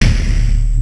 5_bang-snare.wav